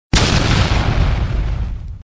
Explosion Sound Effect Free Download
Explosion